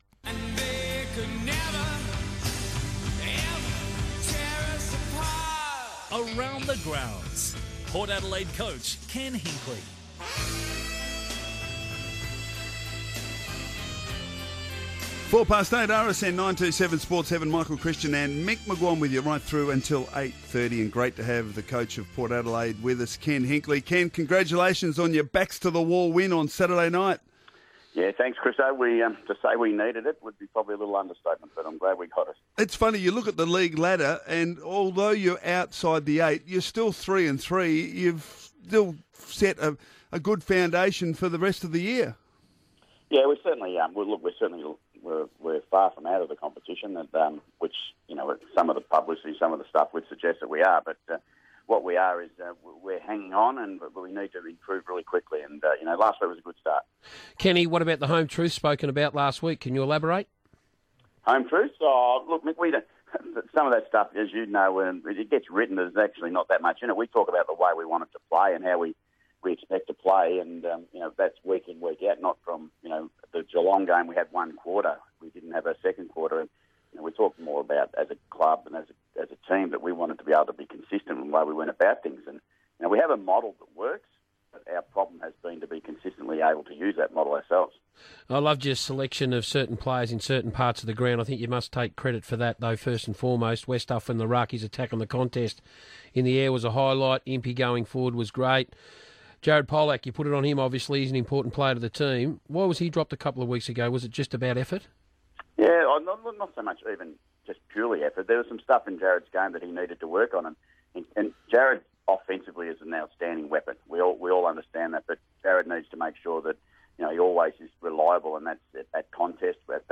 Ken Hinkley talks to Melbourne radio station RSN's Mick McGuane and Michael Christian.